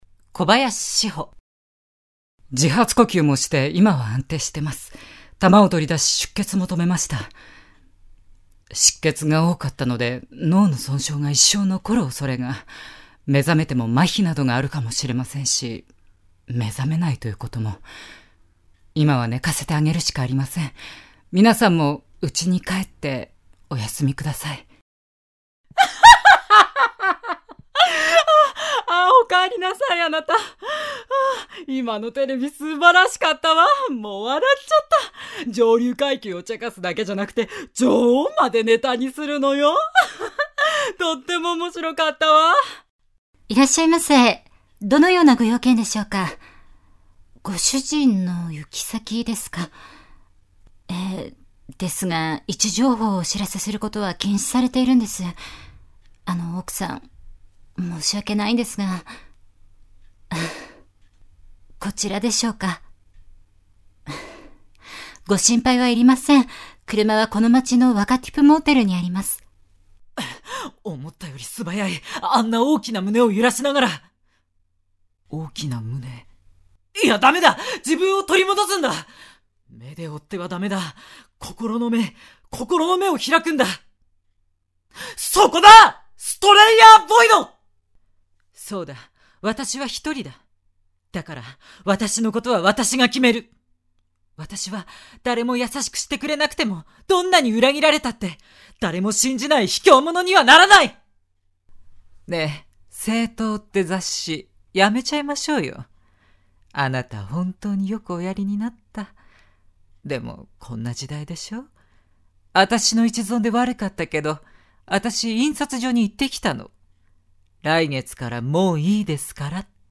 ボイスサンプル
セリフ
voicesample.mp3